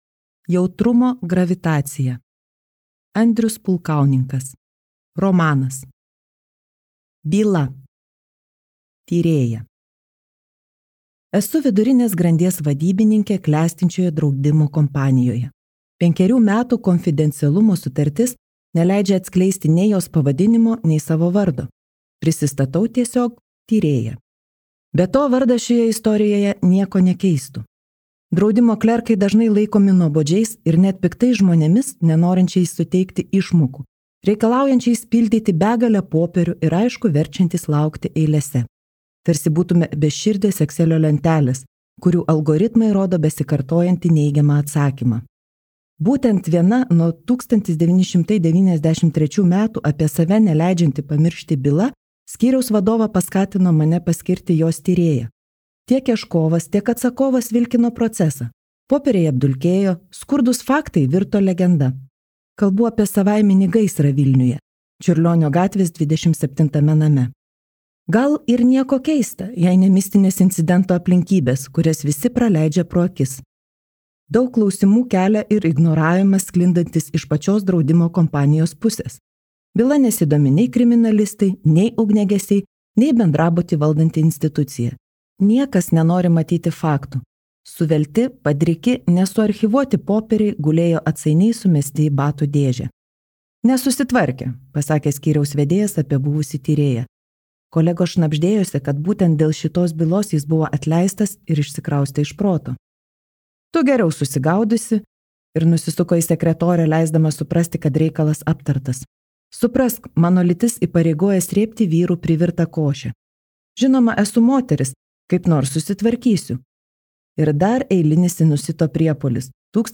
Jautrumo gravitacija | Audioknygos | baltos lankos